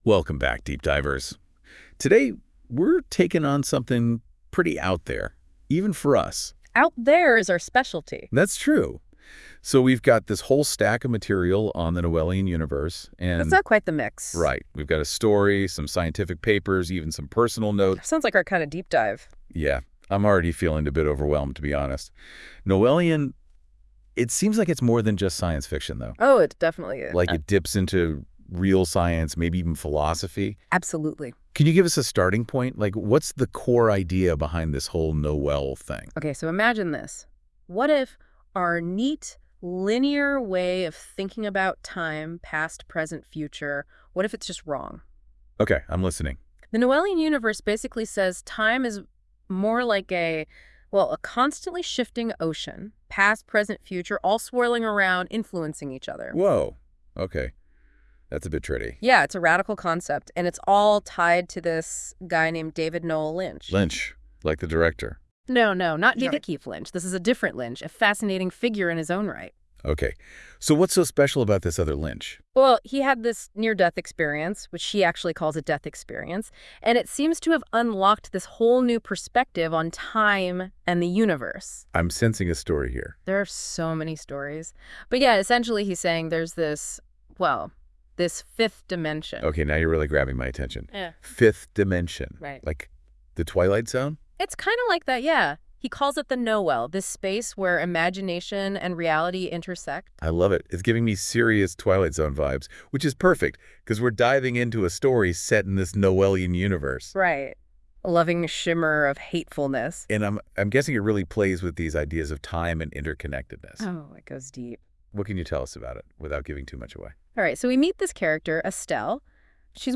A_Loving_Shimmer_of_Hatefulness_Laugh.wav